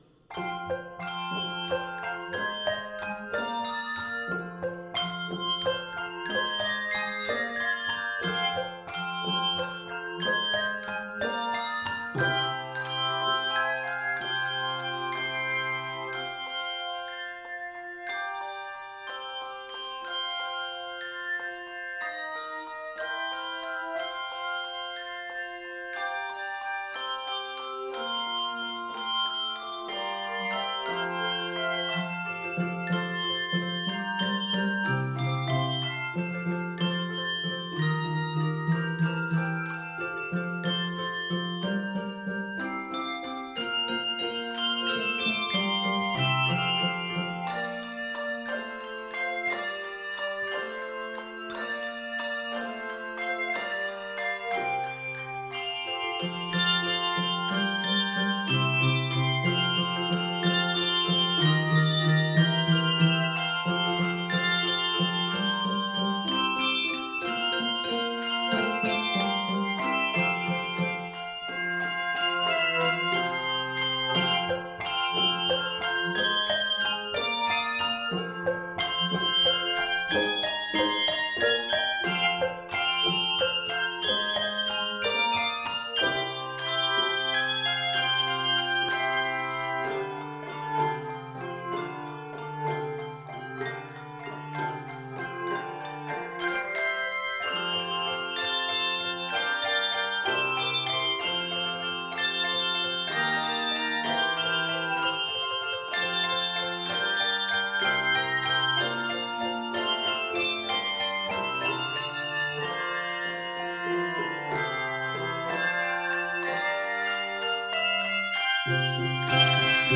is a lighthearted romp, suitable for concert or worship use.
N/A Octaves: 3-5 Level